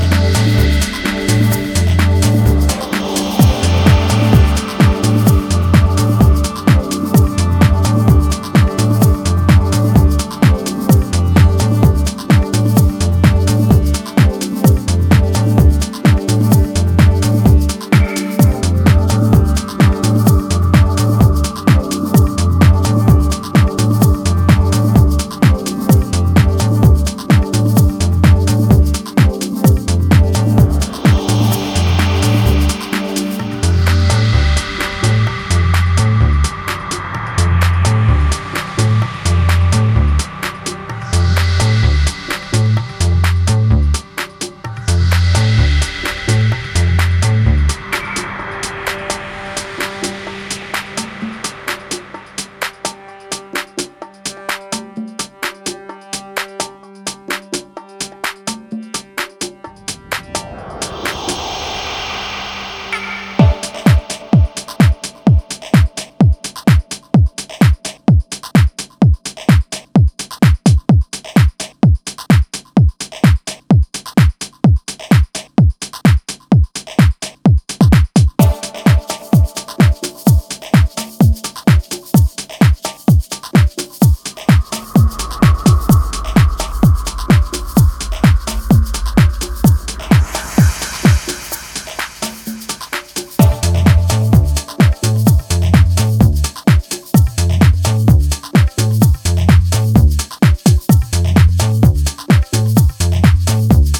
ultra rare deep and tech house